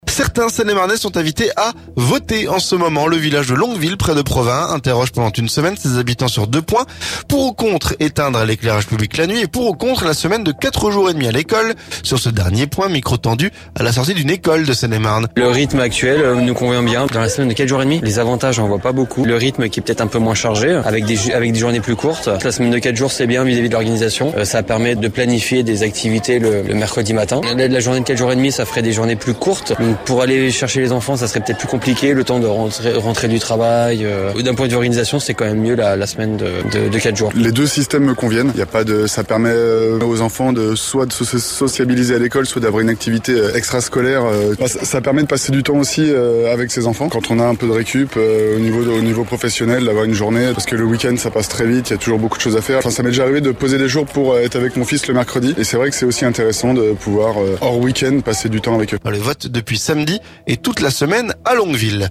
Sur ce dernier point, micro tendu à la sortie d'une école de Seine-et-Marne.